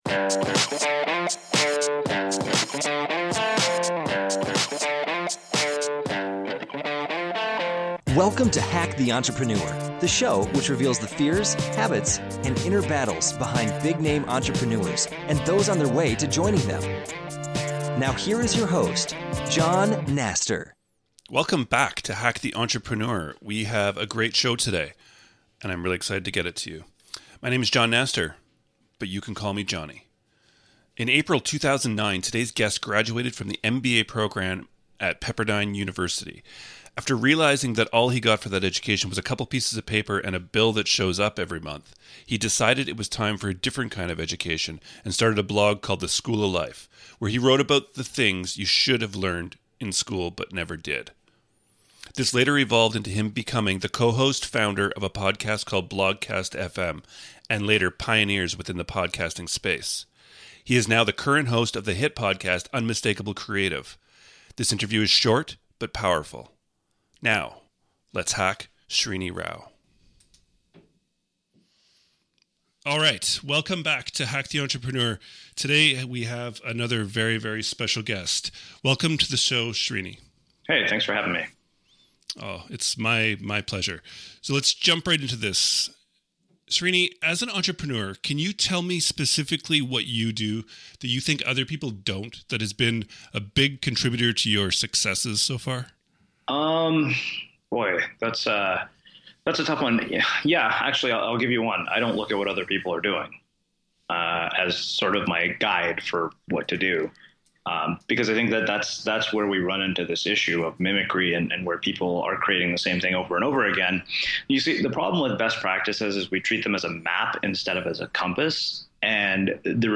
This interview is short, but powerful.